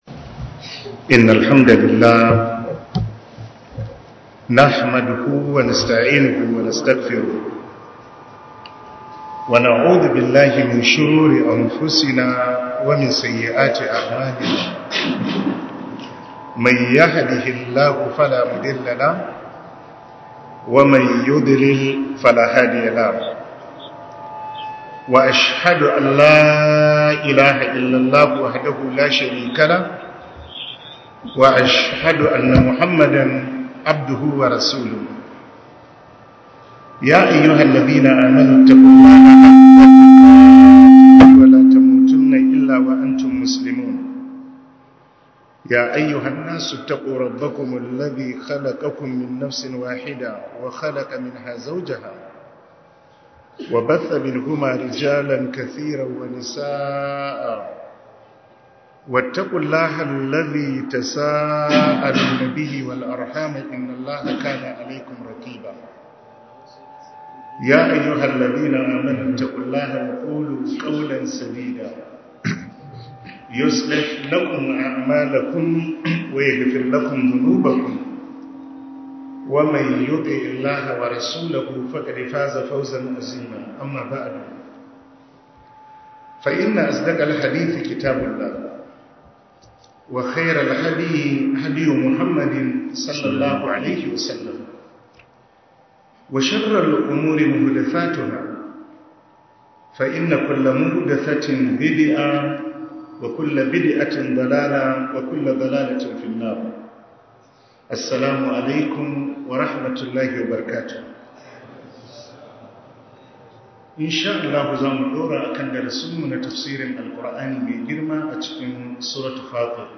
Tafsir